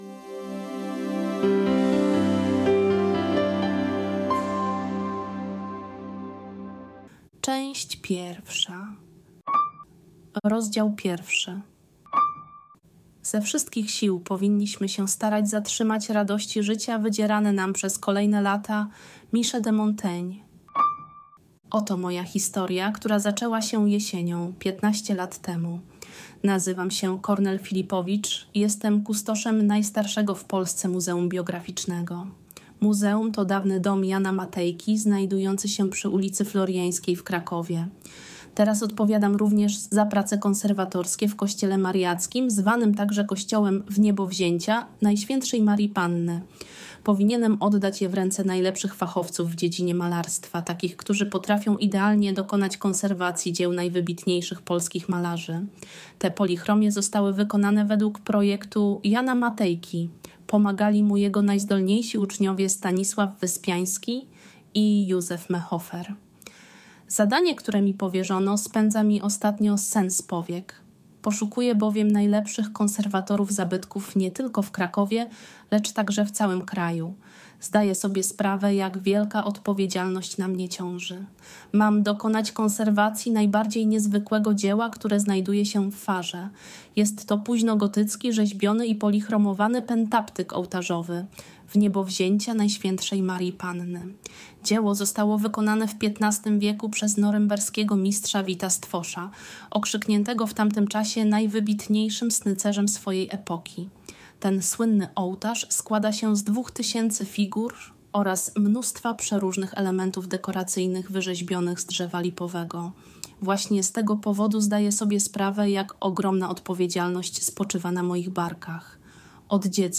Dwadzieścia pięć lat później - audiobook - Wydawnictwo JUT